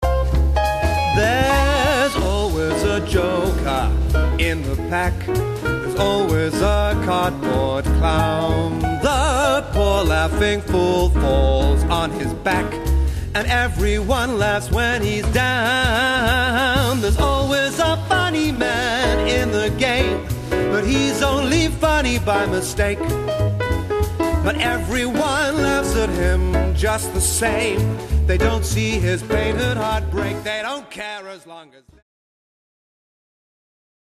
jokermjazz.mp3